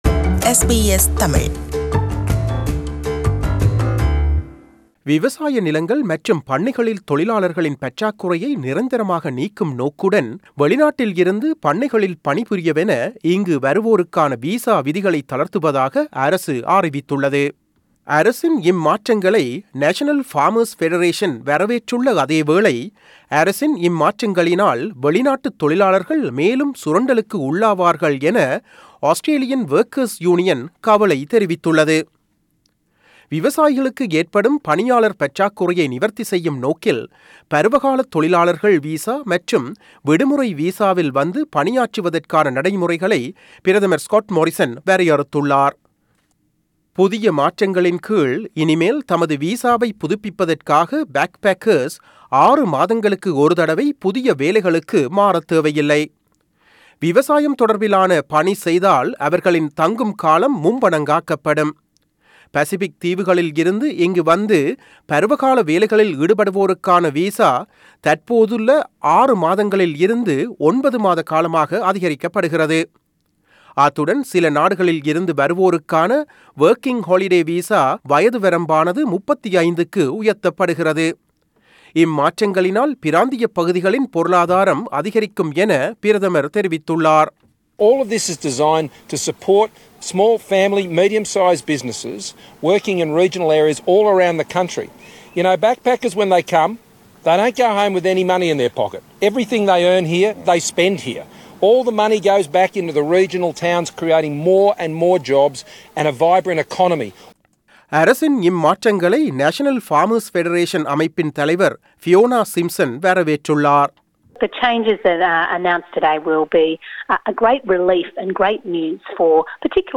செய்திவிவரணத்தை தமிழில் தருகிறார்